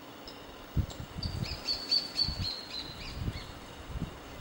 Nome científico: Phacellodomus maculipectus
Nome em Inglês: Spot-breasted Thornbird
Detalhada localização: Sobre Ruta 307 entre El Infiernillo y Tafí del Valle
Condição: Selvagem
Certeza: Gravado Vocal